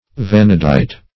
Search Result for " vanadite" : The Collaborative International Dictionary of English v.0.48: Vanadite \Van"a*dite\, n. (Chem.)
vanadite.mp3